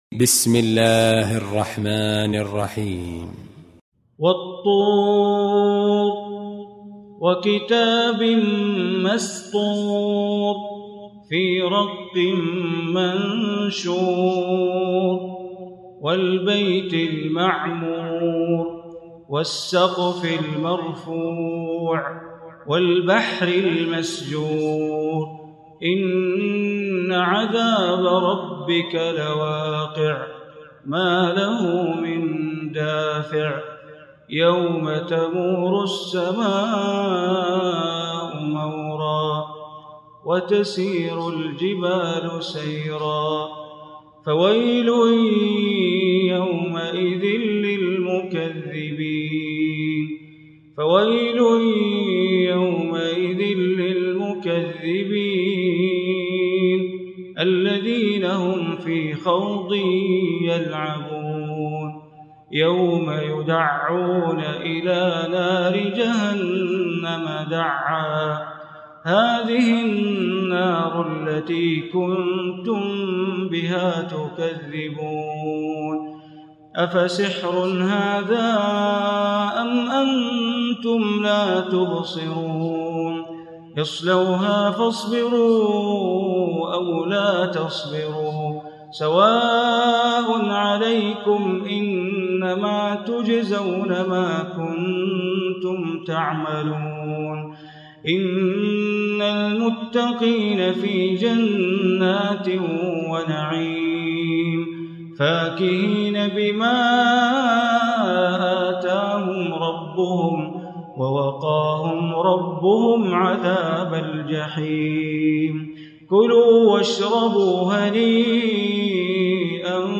Surah Tur Recitation by Sheikh Bandar Baleela
Surah Tur, listen online mp3 tilawat / recitation in Arabic recited by Imam e Kaaba Sheikh Bandar Baleela.